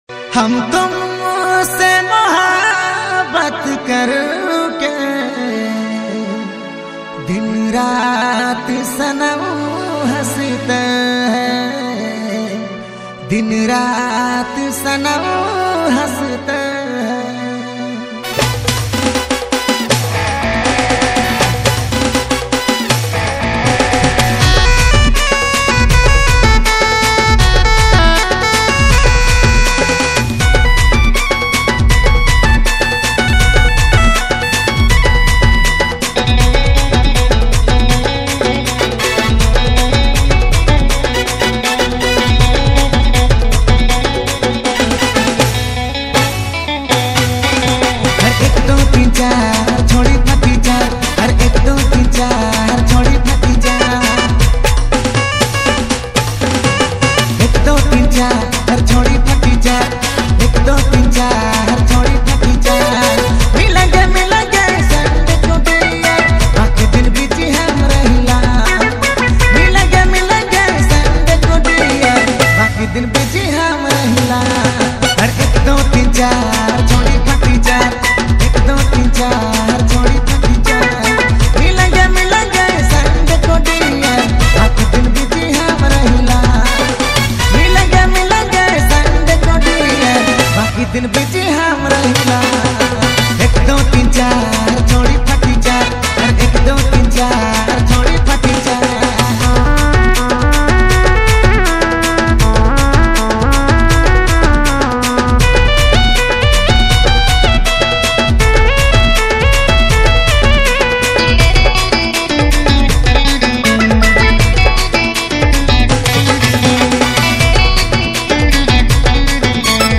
Enjoy the catchy beats
a popular Nagpuri song of 2020